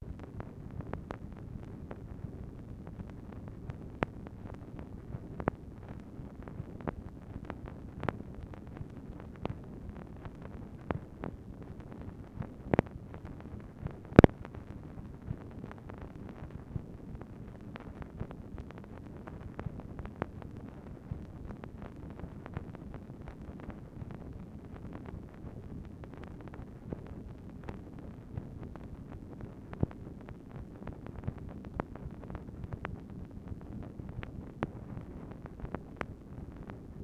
MACHINE NOISE, November 23, 1964
Secret White House Tapes | Lyndon B. Johnson Presidency